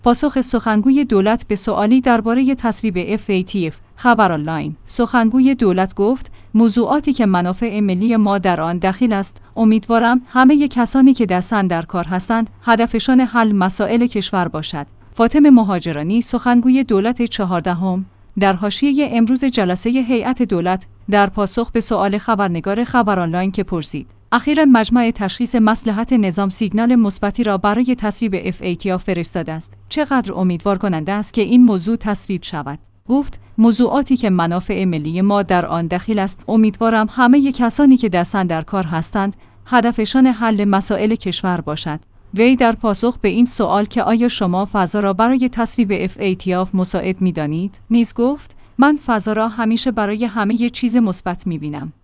پاسخ سخنگوی دولت به سوالی درباره تصویب FATF